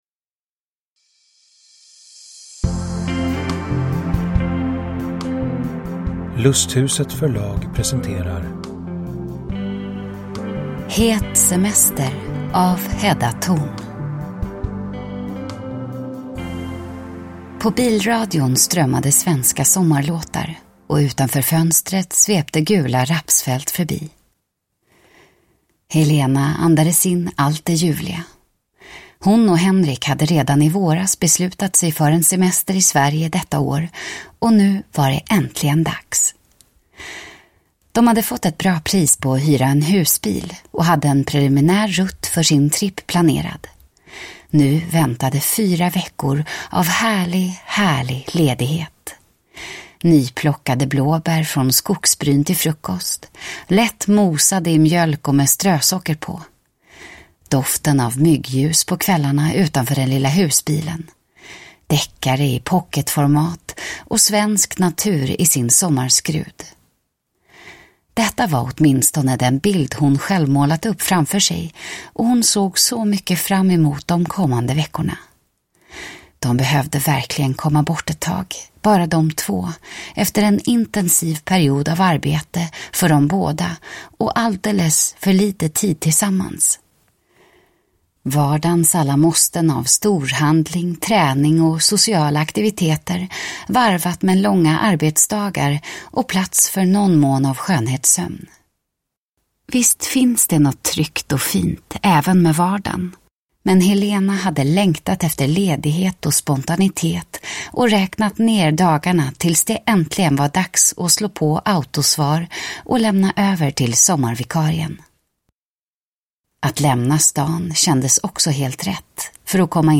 Het semester (ljudbok) av Hedda Torn